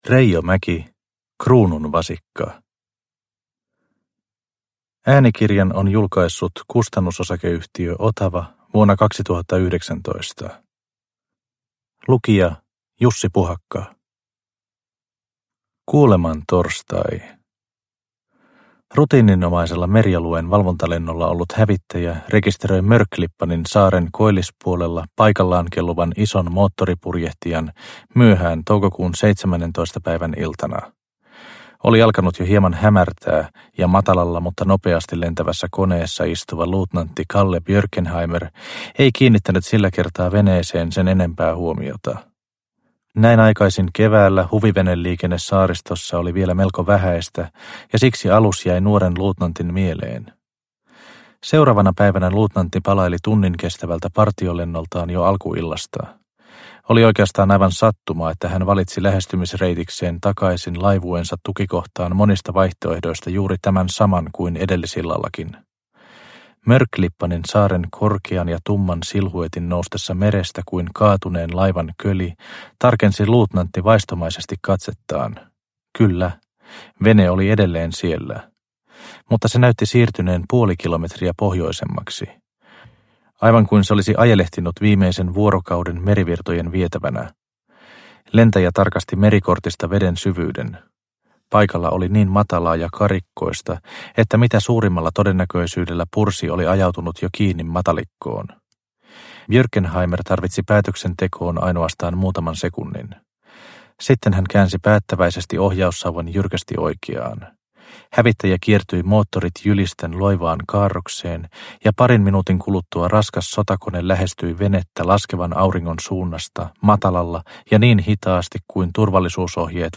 Kruunun vasikka – Ljudbok – Laddas ner